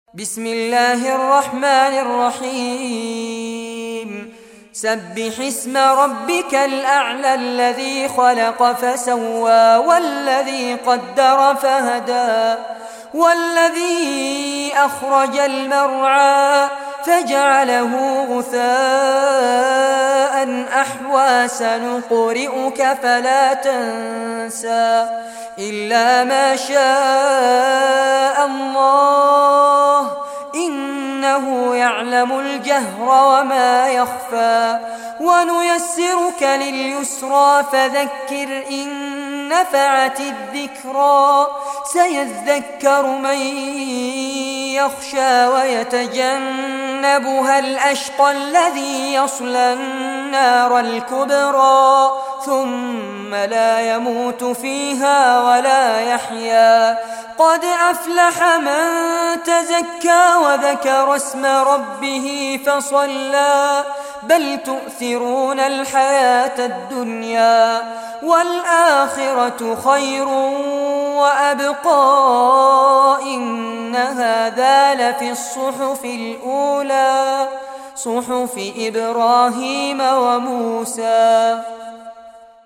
Surah Al-Ala Recitation by Fares Abbad
Surah Al-Ala, listen or play online mp3 tilawat / recitation in Arabic in the beautiful voice of Sheikh Fares Abbad.